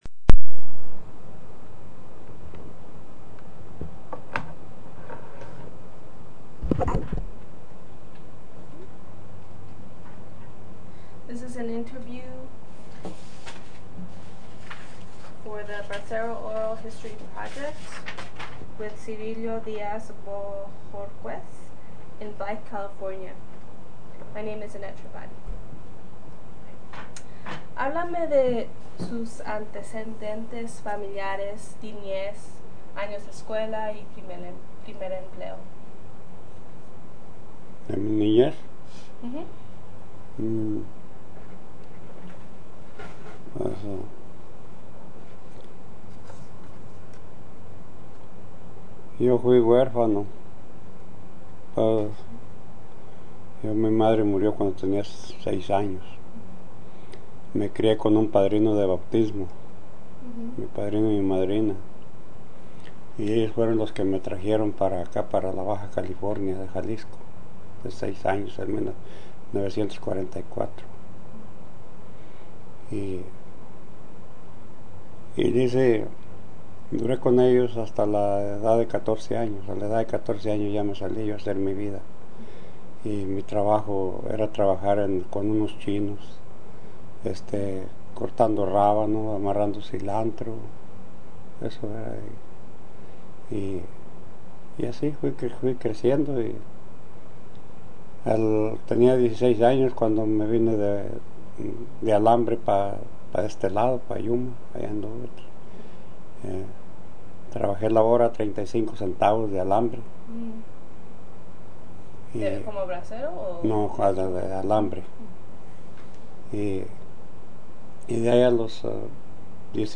he worked in Arizona picking cotton, lettuce, melon, and watermelon. Summary of Interview